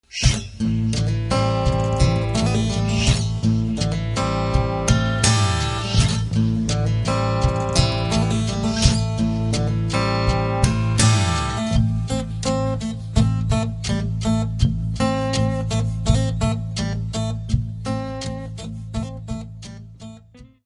chitarra acustica e loops
chitarra 7 corde